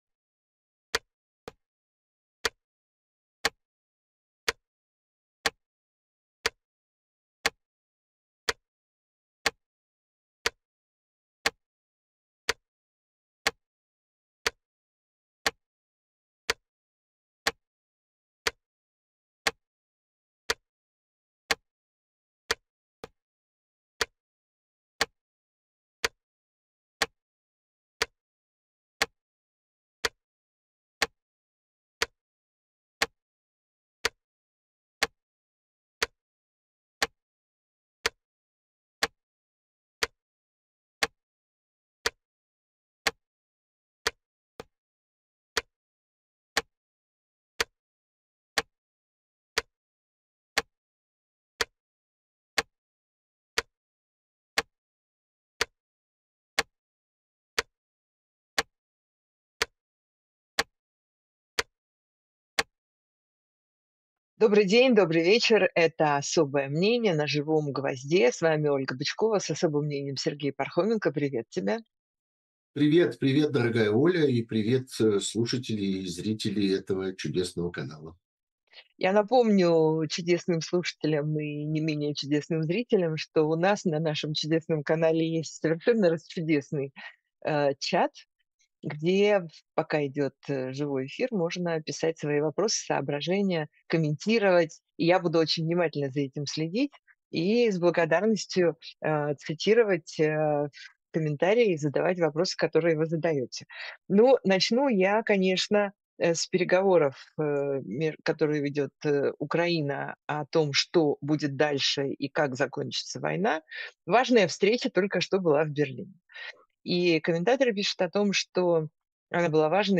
Эфир ведёт Ольга Бычкова